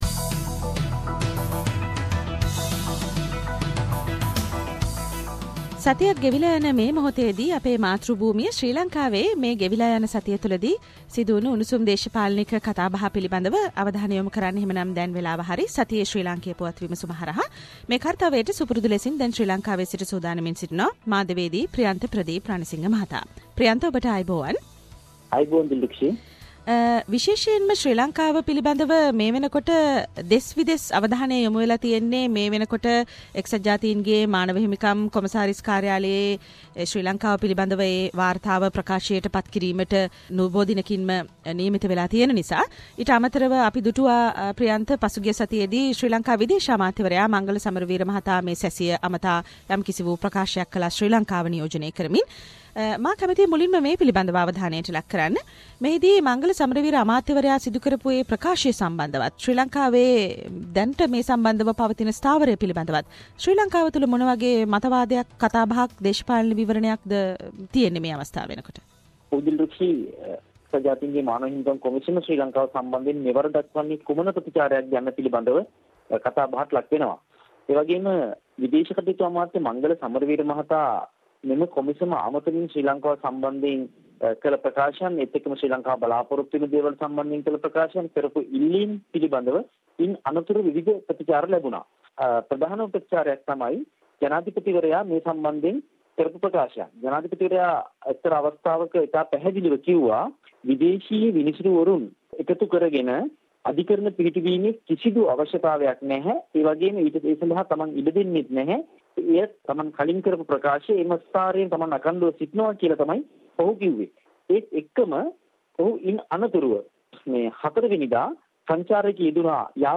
Weekly Sri Lankan political highlights - The comprehensive wrap up of the highlighted political incidents in Sri Lanka…..Senior Journalist Mr